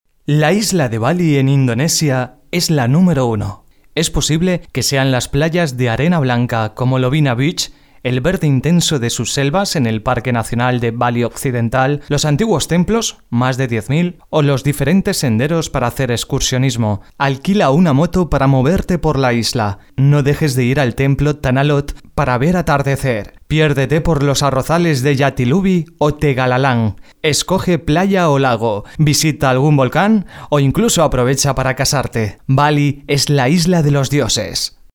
Sprechprobe: Sonstiges (Muttersprache):
Durante 25 años en el mundo de la radio mi voz ha pasado por tooodos los registros que te puedas imaginar, los más serios, divertidos, tensos, tontos... todo ello plasmado en cuñas de radio, spots de Tv, programas musicales, de noticias, como reportero en unidad móvil, en presentación de eventos y charlas, en definitiva una voz versátil preparada para ser modulada y adaptada a cualquier trabajo